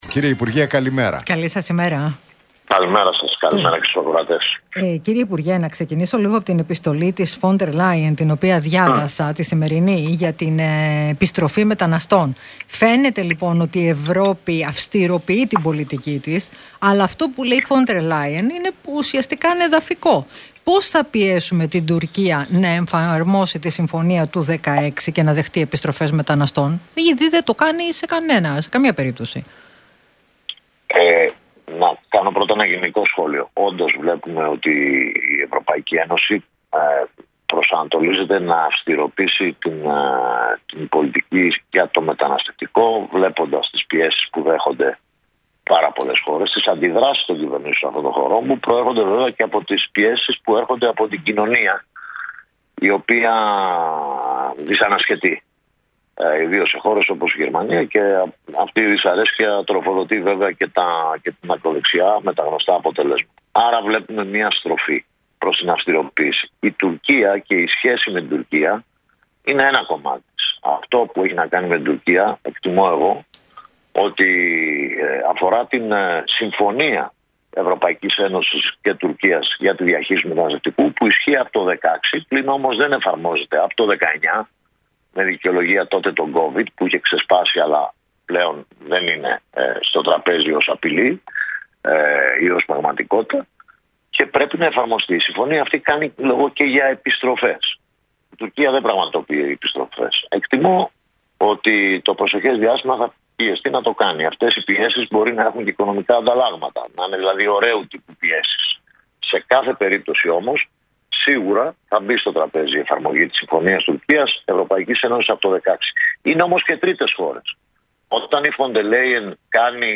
Νίκος Παναγιωτόπουλος στον Realfm 97,8: Η Ε.Ε. προσανατολίζεται να αυστηροποιήσει την πολιτική για το μεταναστευτικό - Τι είπε για τις αυξημένες ροές
μίλησε ο υπουργός Μετανάστευσης και Ασύλου, Νίκος Παναγιωτόπουλος